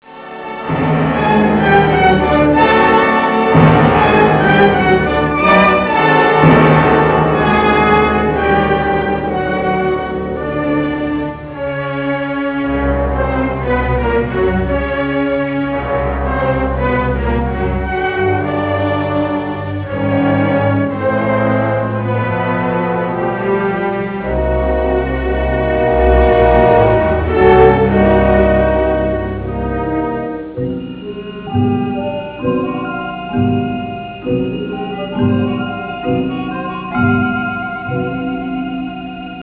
Original track music